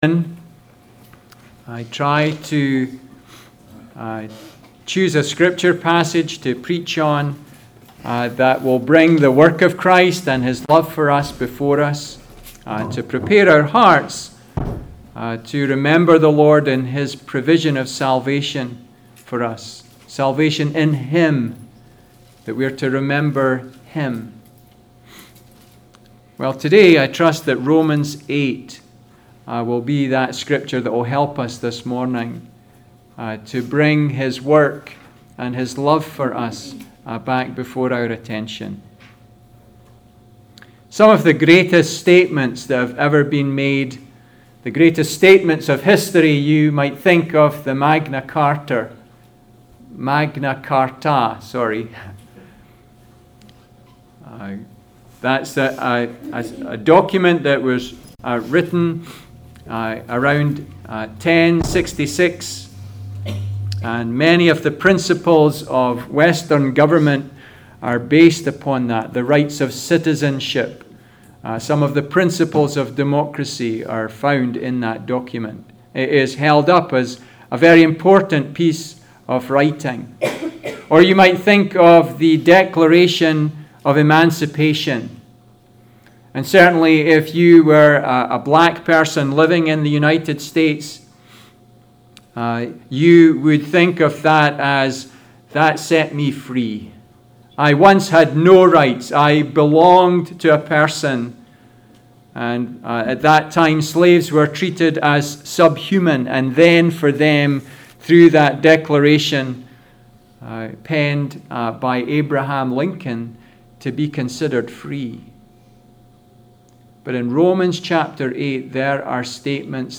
Passage: Romans 8:28-30 Service Type: Sunday Service